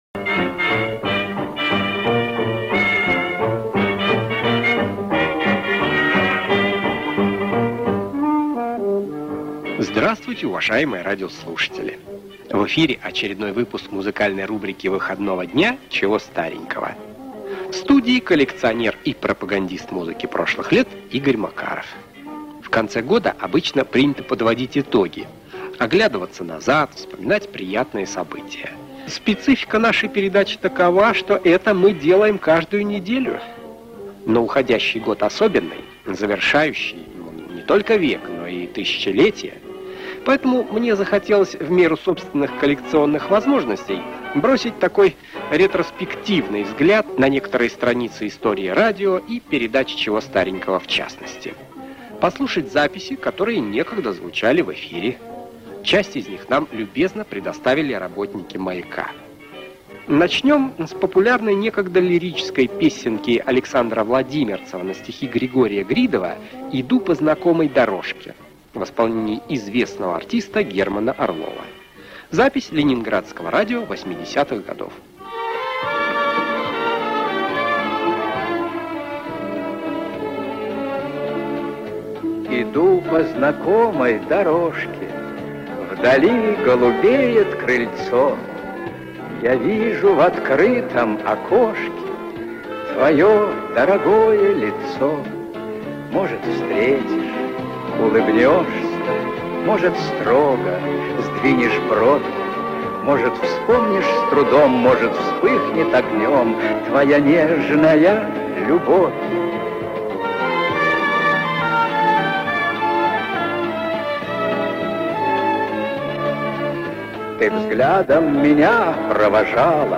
Тенор с оркестром
Народная (городская) песня
Оркестр (Folksong Orch. Accomp.)
Место записи: Нью-Йорк